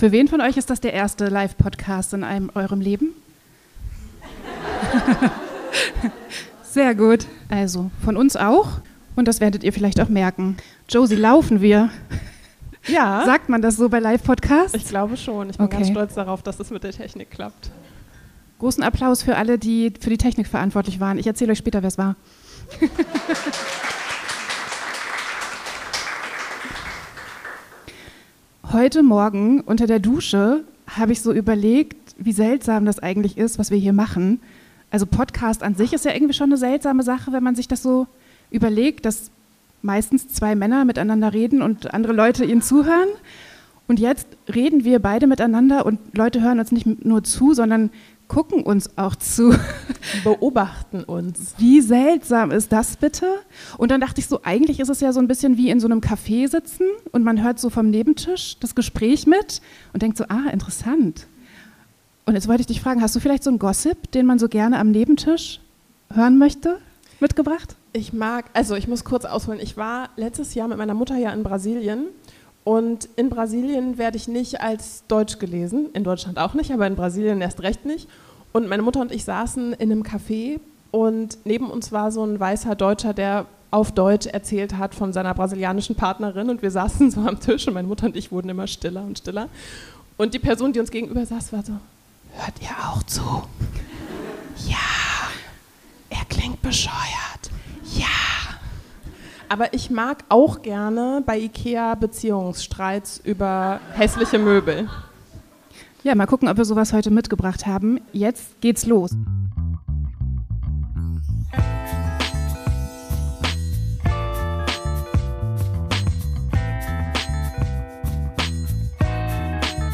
Unser erstes Mal live vor Publikum! Wir spielen »Stadt, Land, Insel«.